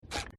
FootstepHandlerClown2.mp3